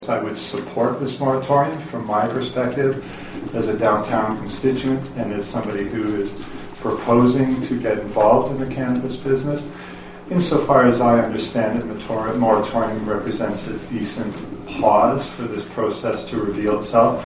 The public hearing in Nelson Monday for recreational cannabis businesses yielded positive feedback.